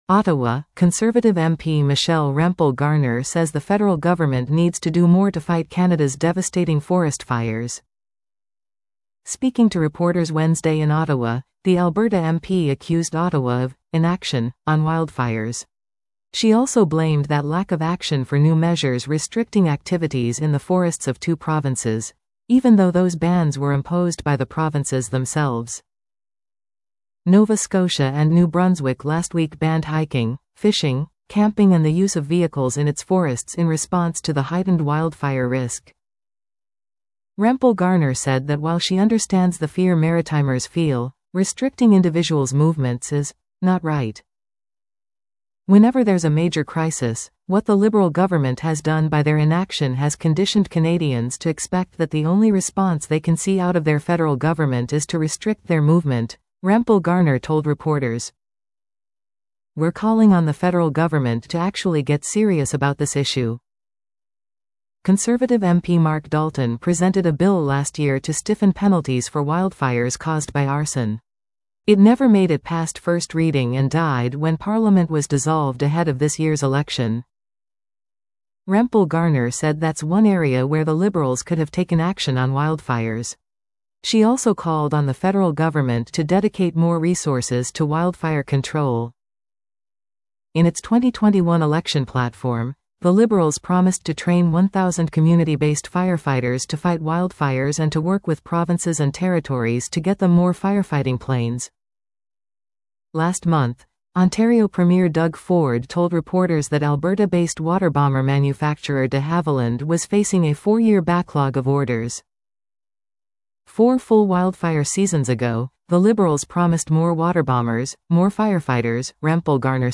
OTTAWA — Conservative MP Michelle Rempel Garner says the federal government needs to do more to fight Canada's devastating forest fires. Speaking to reporters Wednesday in Ottawa, the Alberta MP accused Ottawa of "inaction" on wildfires.